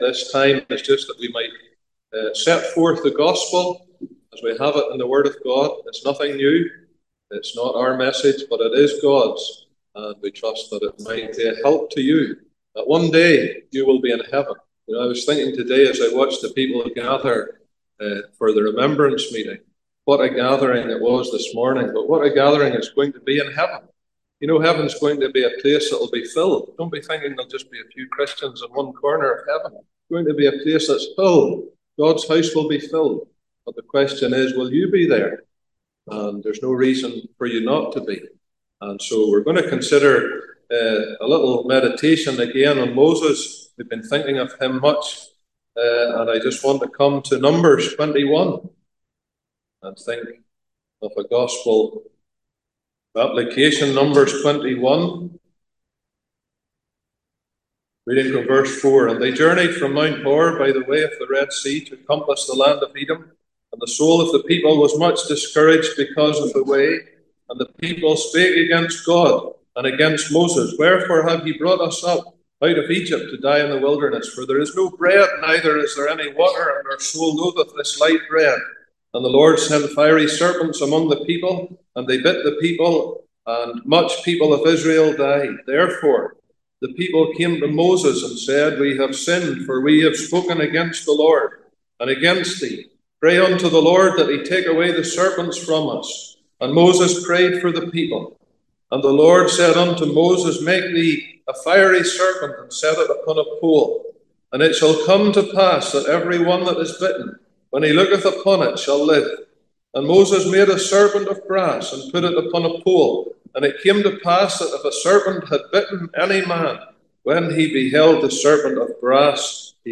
Cooroy Gospel Hall